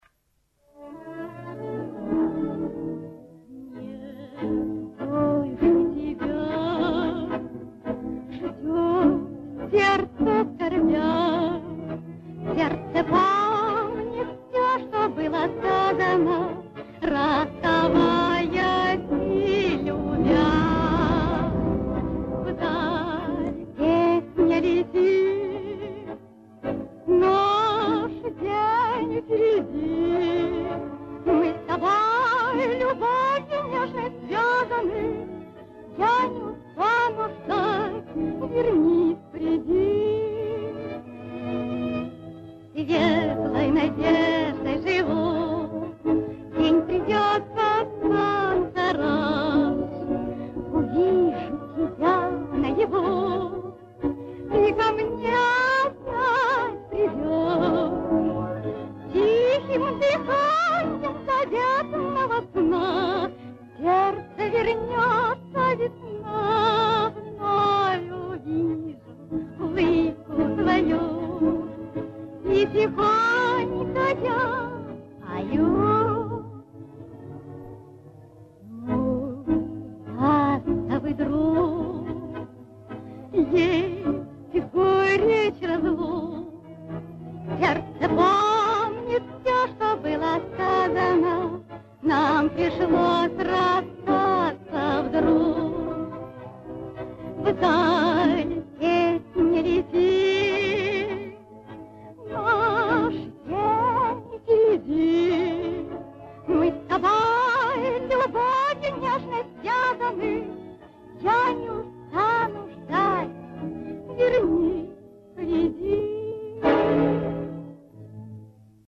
Вторая песня (фрагмент из фильма)
В интернете есть информация, что песни исполняет эта актриса